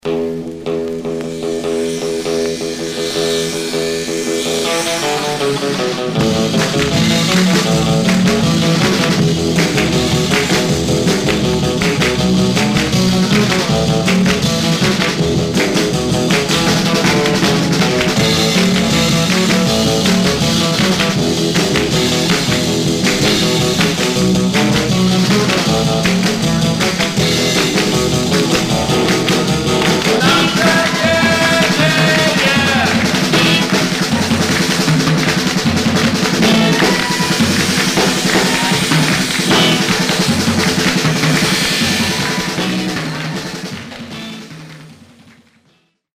Mono
R & R Instrumental